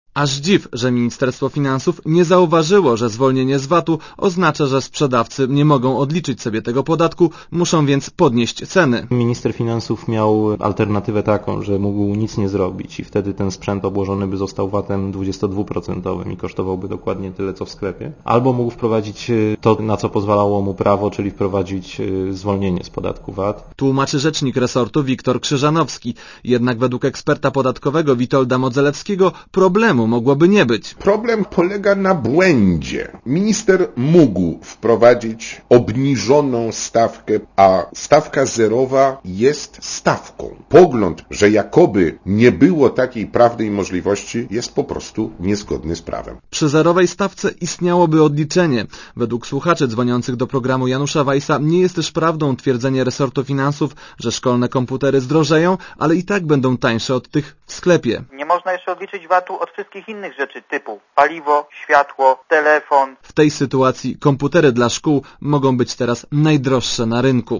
Posłuchaj relacji reportera Radia Zet (262kB)